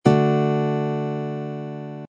D Major open chord
d-major-open-chord.mp3